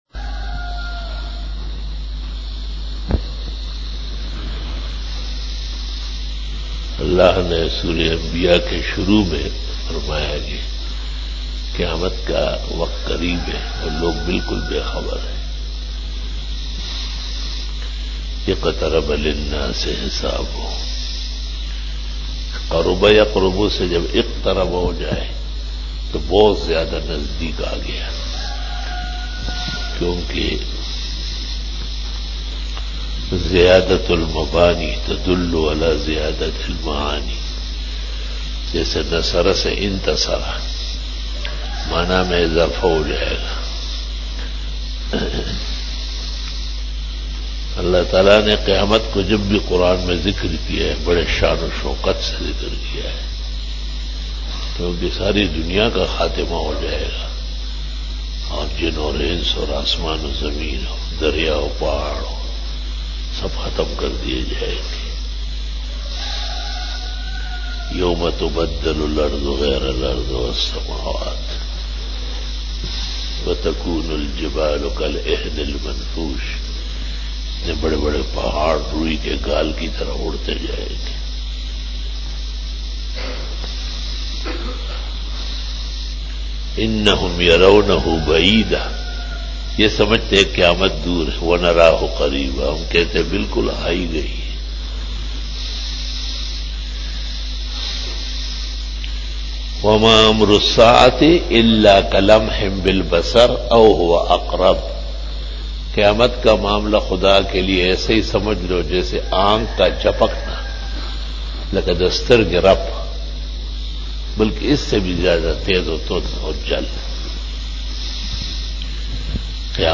بیان بعد نماز فجر بروز بدھ 12 جمادی الاول 1441ھ/ 08 جنوری 2020ء"